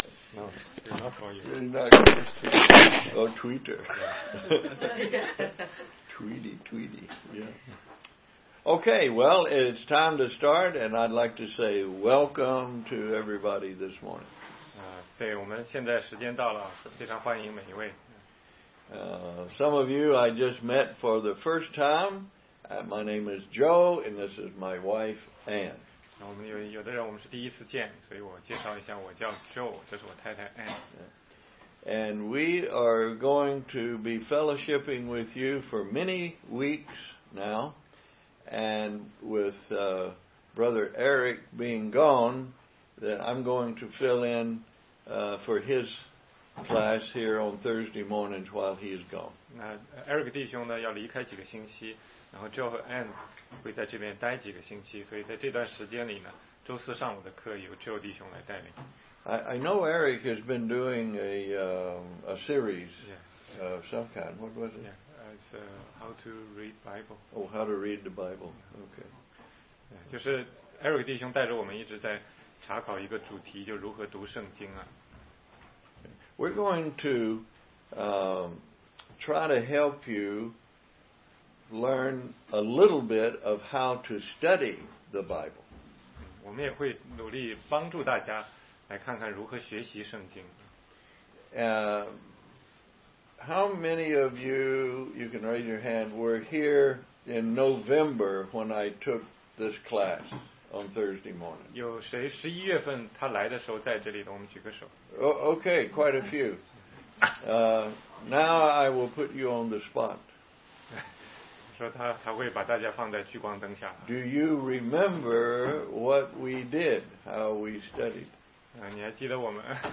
16街讲道录音 - 研读圣经的方法示例系列之一